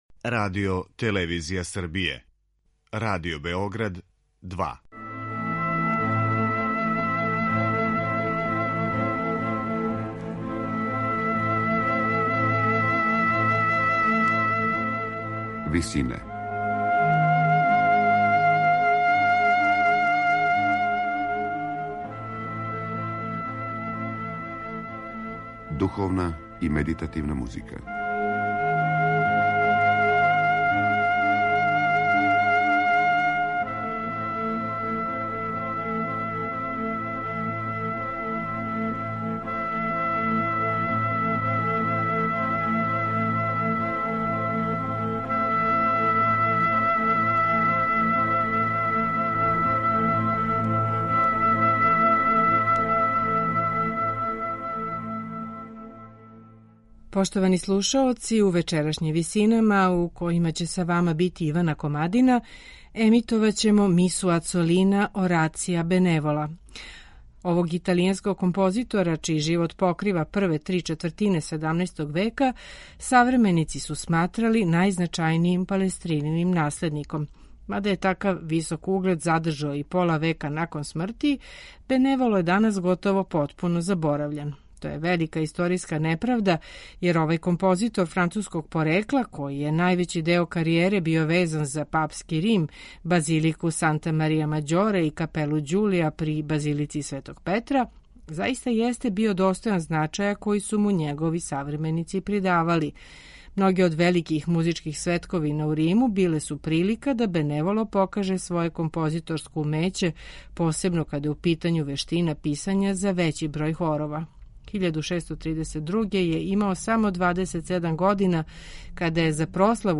„Missa Azzolina" писана је у част кардинала Ацолинија, кога је папа Александар послао као саветника шведској краљици Кристини. Беневоло је ту мису осмислио за два петогласна хора, са удвојеним сопранским деоницама, а ми ћемо је чути у интерпретацији ансамбла „Le Concert Spirituel", под управом Ервеа Никеа.